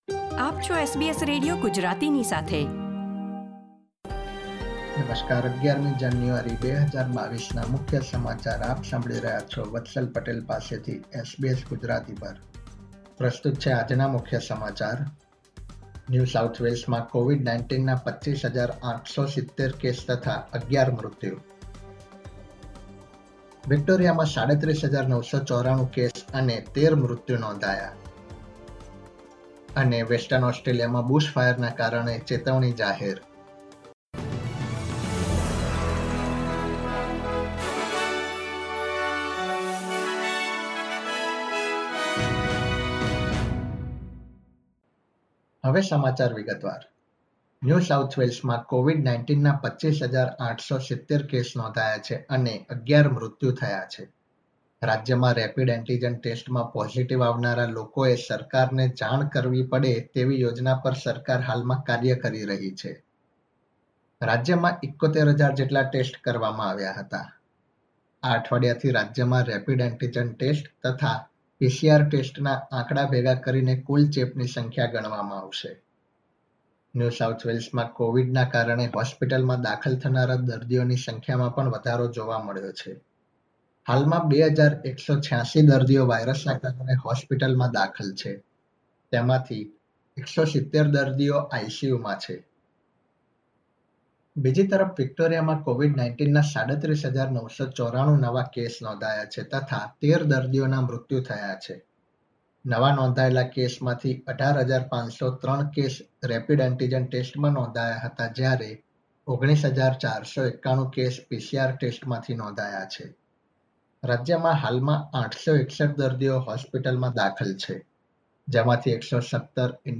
SBS Gujarati News Bulletin 11 January 2022